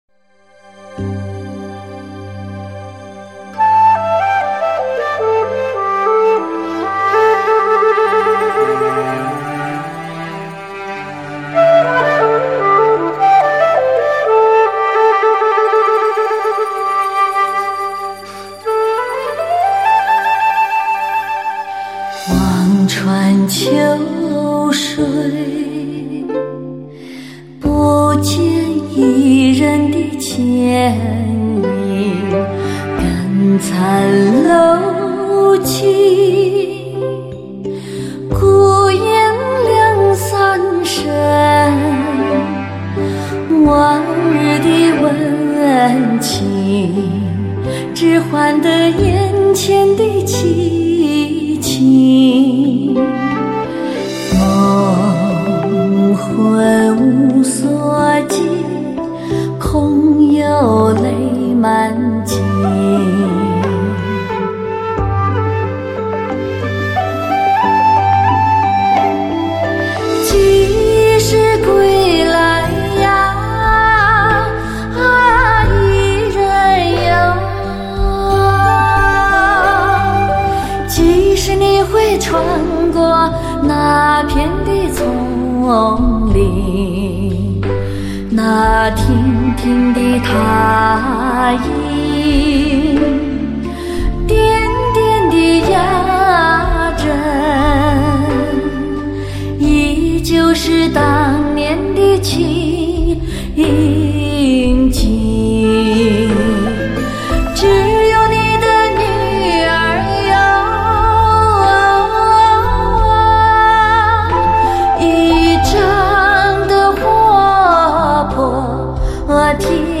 醉人的嗓音唤醒你那沉睡已久的耳膜。
为低音质MP3